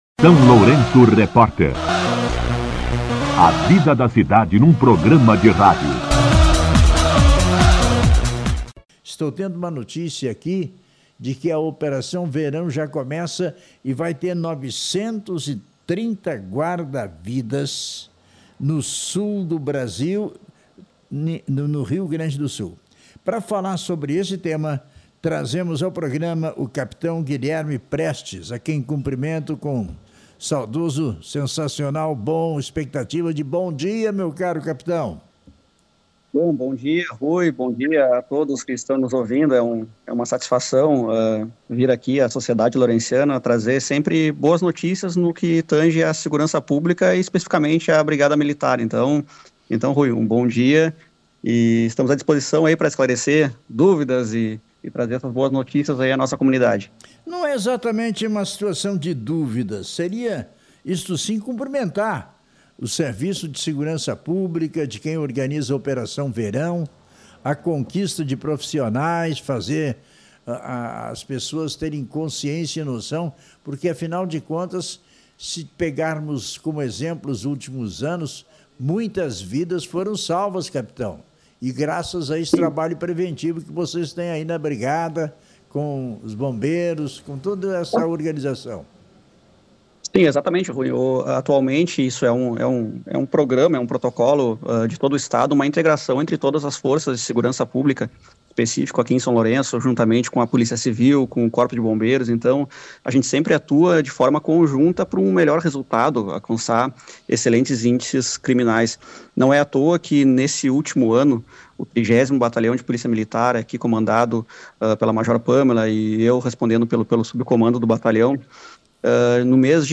Entrevista com o Capitão da Brigada Militar